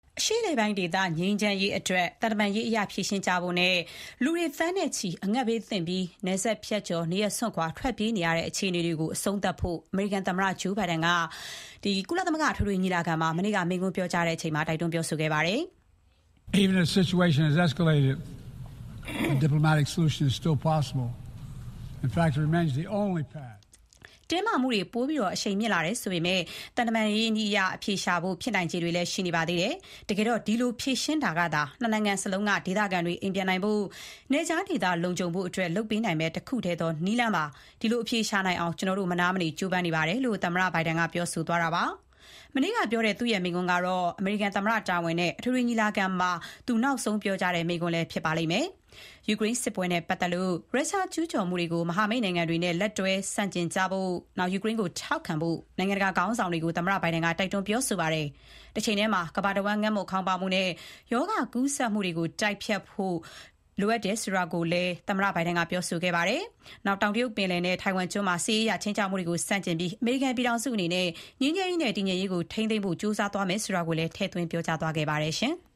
အရှေ့အလယ်ပိုင်း ငြိမ်းချမ်းရေးမှာ သံတမန်ရေးအရ ဆက်လက် ဖြေရှင်းကြဖို့နဲ့ သန်းနဲ့ချီတဲ့ လူတွေ အငတ်ဘေးသင့်ပြီး နယ်ခြားကျော် နေရပ်စွန့်ခွာထွက်ပြေးနေရတဲ့ စစ်ပွဲတွေအဆုံးသတ်ဖို့ အမေရိကန် သမ္မတ Joe Biden က မနေ့က ကုလသမဂ္ဂ အထွေထွေညီလာခံမှာ မိန့်ခွန်းမှာ တိုက်တွန်း ပြောကြားသွားပါတယ်။